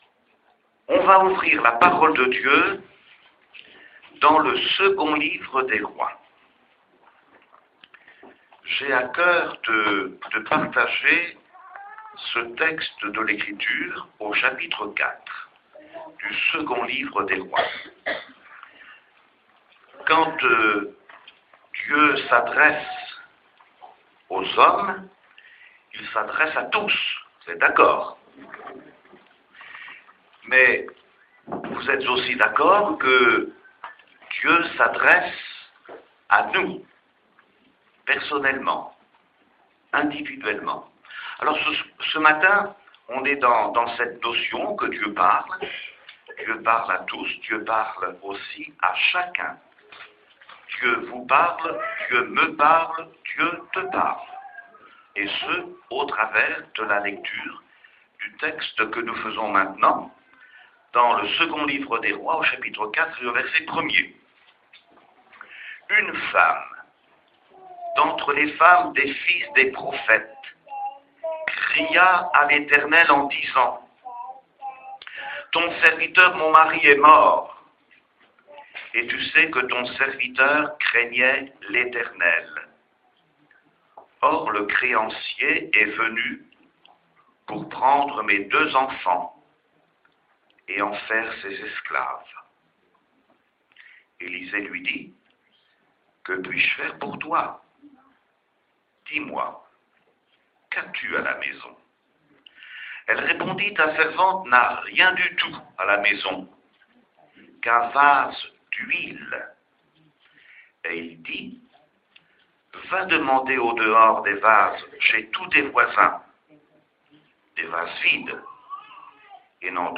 Message audio